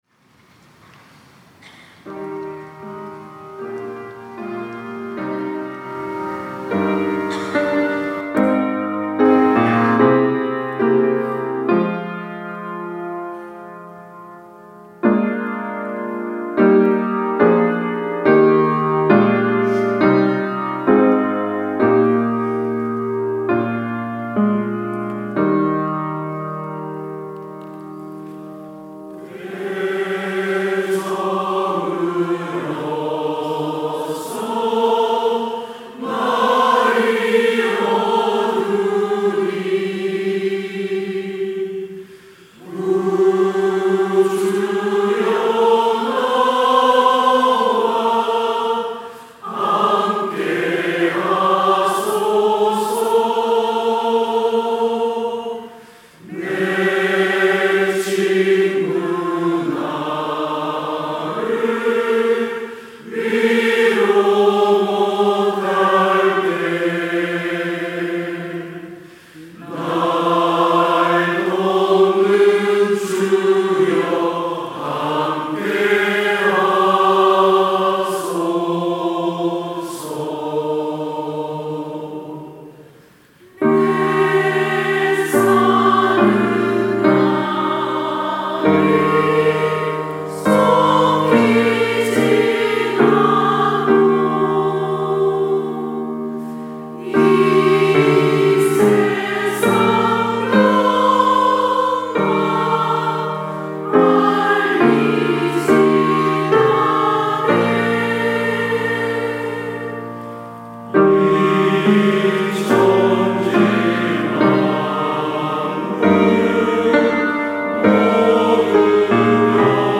특송과 특주 - 때 저물어서 날이 어두니